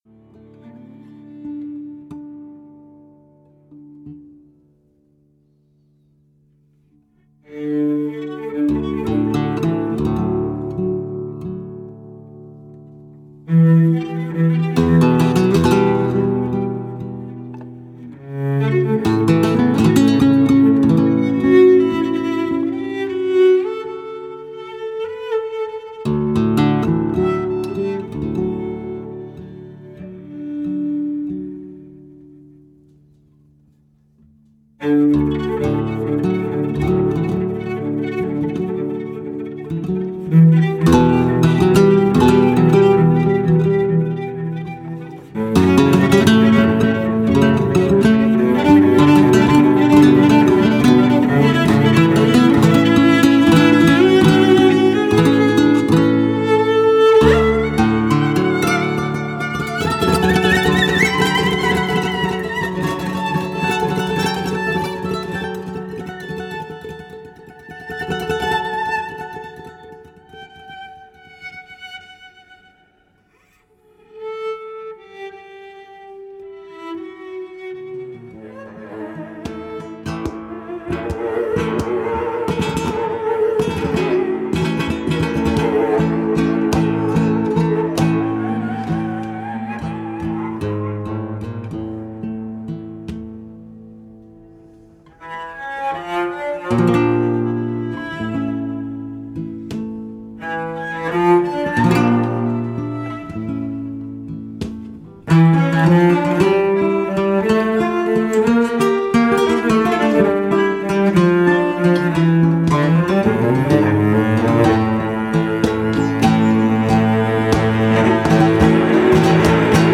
Flamenco guitar
trained to play the cello in its Western classical form
harmonium